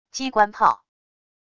机关炮wav音频